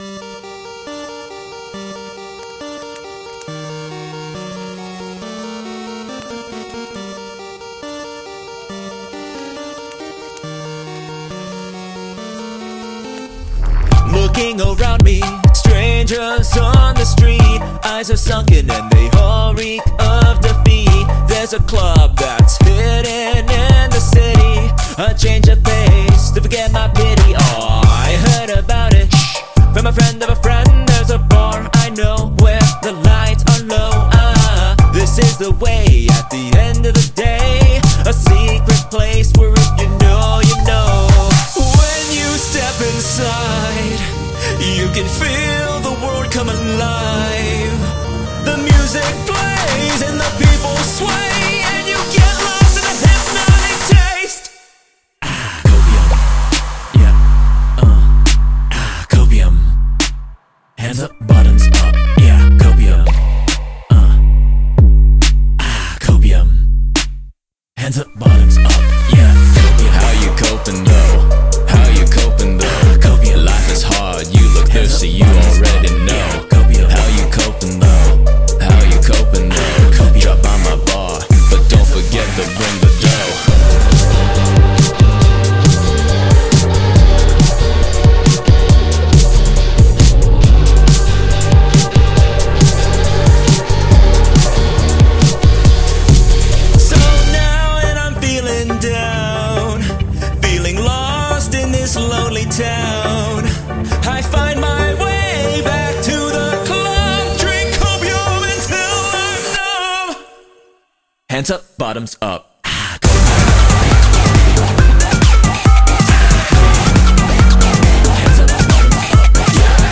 BPM69-138
Audio QualityCut From Video